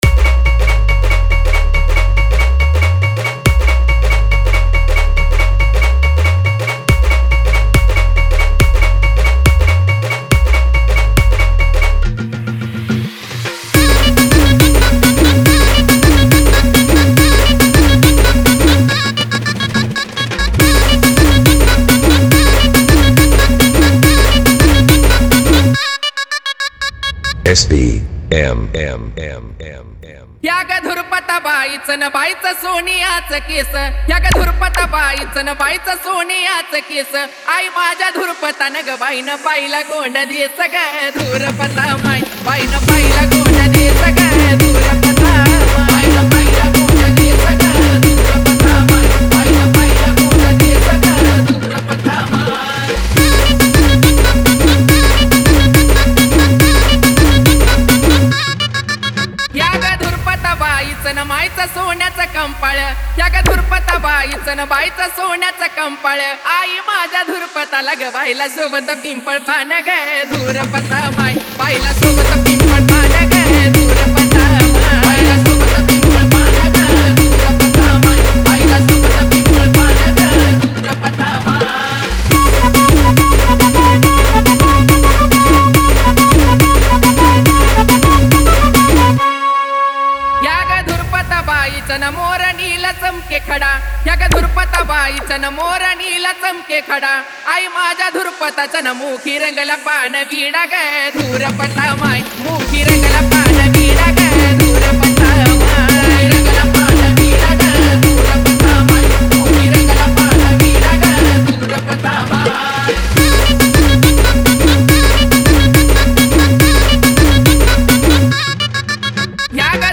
Category : Navratri Dj Remix Song